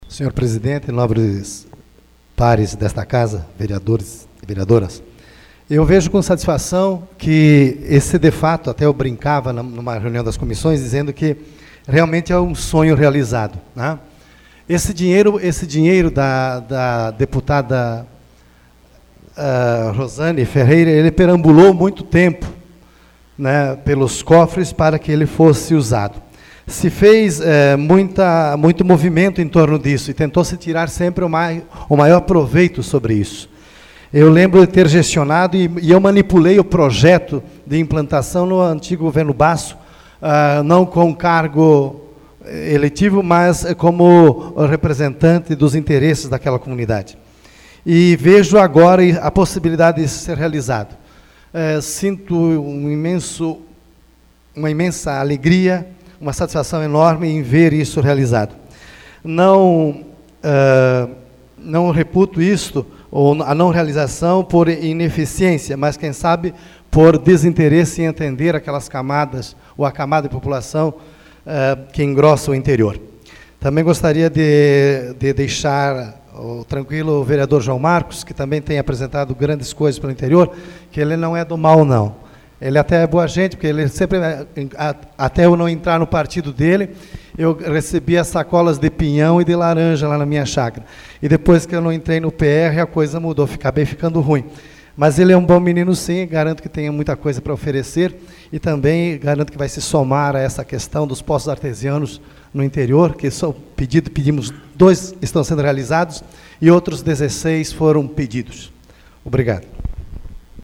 Discussão AVULSO 18/03/2014 Luiz Rossatto